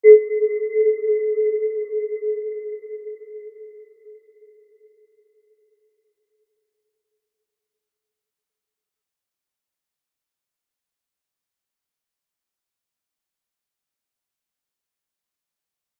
Aurora-B4-mf.wav